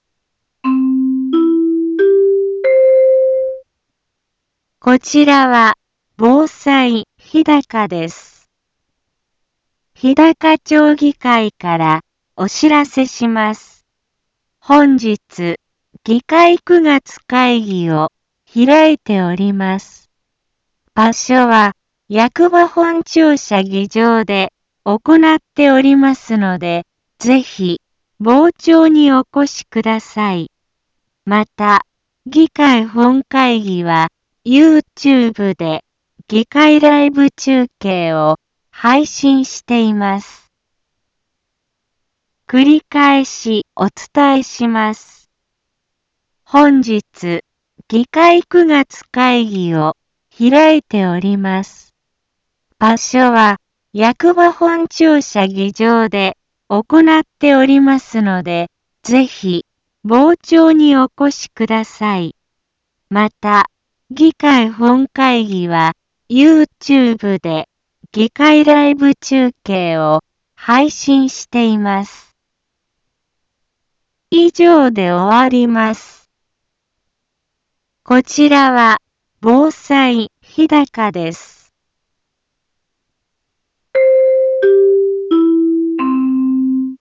Back Home 一般放送情報 音声放送 再生 一般放送情報 登録日時：2019-09-11 10:03:05 タイトル：9月議会のお知らせ インフォメーション：こちらは、防災日高です。